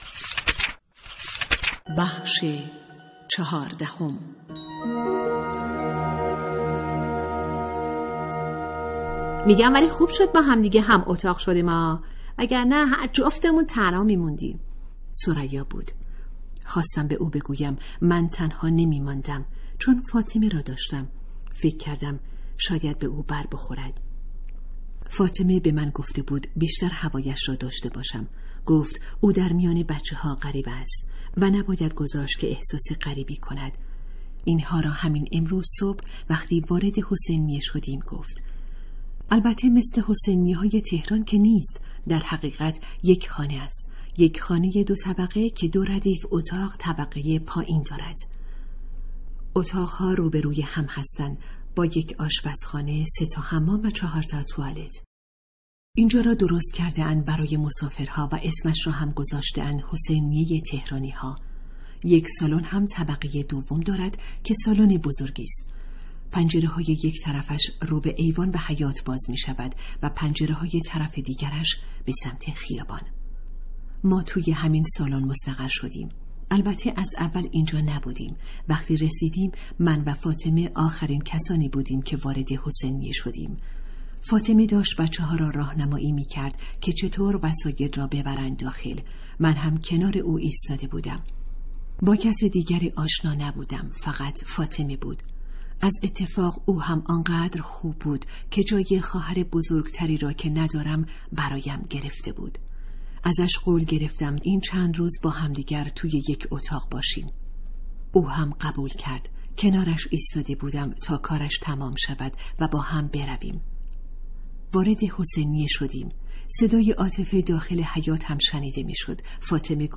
کتاب صوتی | دختران آفتاب (14)
کتاب صوتی دختران آفتابیک داستان بلند درباره دختران ایران